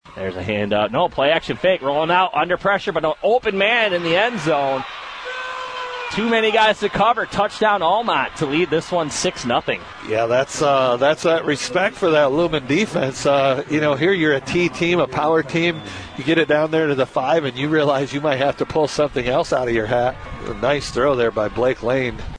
Audio from WKHM’s radio broadcast